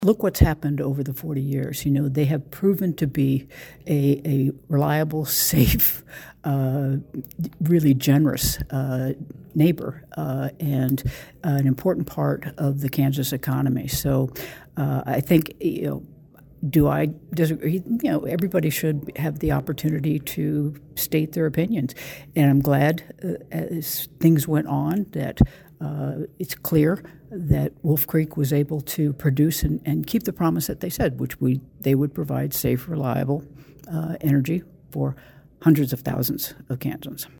Kansas Governor Laura Kelly, one of many dignitaries in attendance for Friday’s celebration, stated during her remarks that the plant, which has provided power to more than 20 percent of the state of Kansas, has helped to reduce the use of fossil fuels and reduce carbon emissions throughout its history.